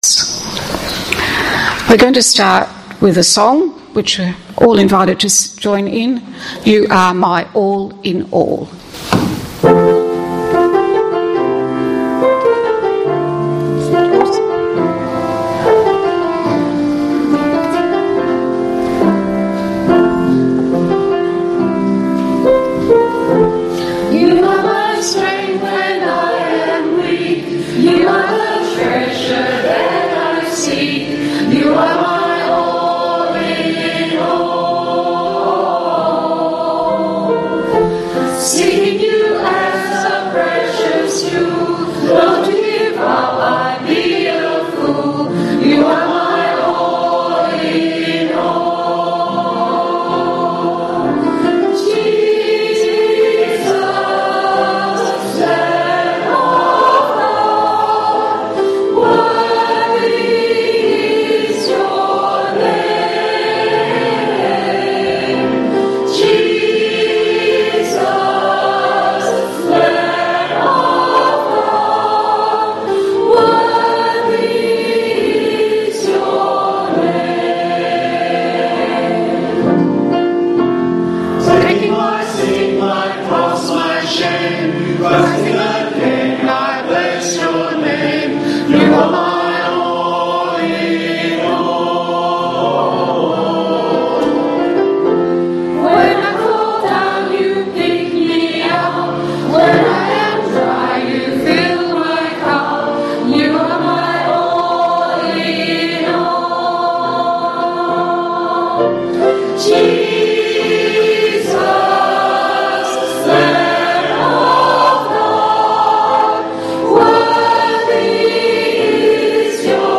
A celebration in song!